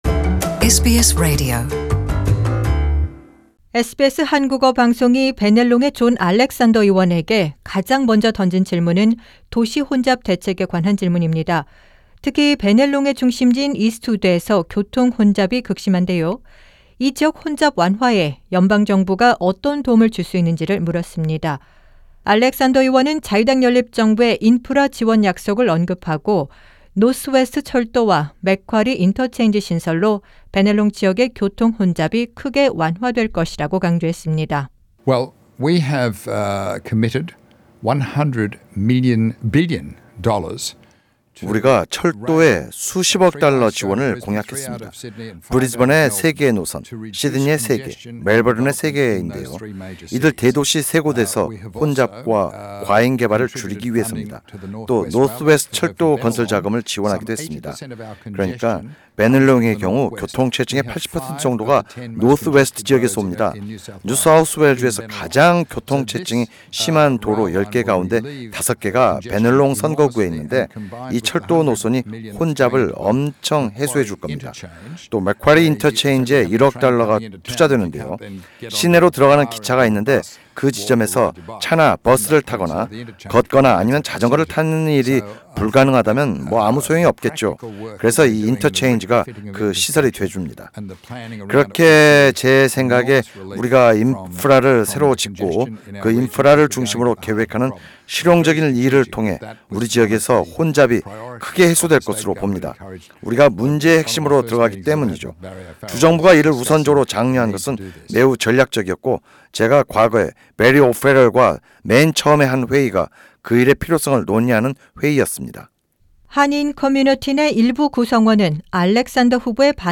SBS 한국어 방송은 베네롱의 존 알렉산더 의원과 한 간단한 인터뷰에서 다음과 같은 질문을 했다.